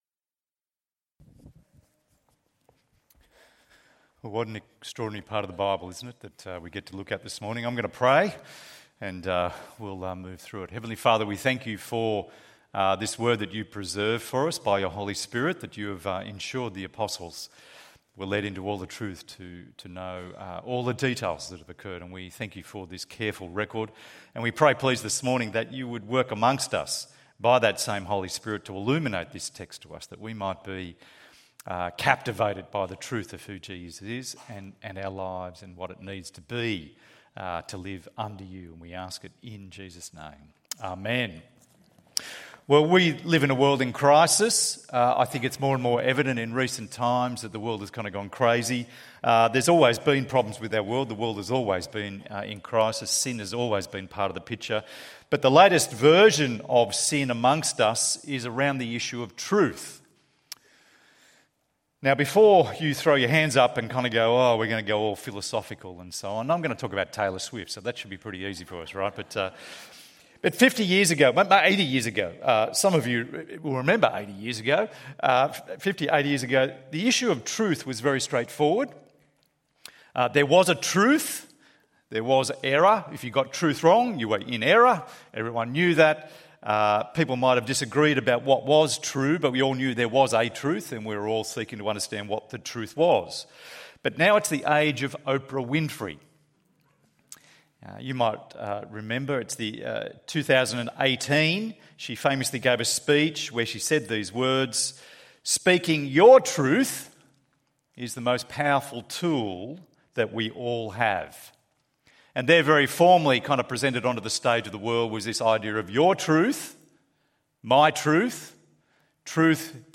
Behold Your King! ~ EV Church Sermons Podcast